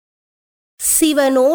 தரம் 9 இல் கல்வி பயிலும் சைவநெறிப் பாடத்தை கற்கும் மணவர்களின் நன்மை கருதி அவர்கள் தேவாரங்களை இலகுவாக மனனம் செய்யும் நோக்கில் இசைவடிவக்கம் செய்யப்பட்ட தேவாரப்பாடல்கள் இங்கே பதிவிடபட்டுள்ளன.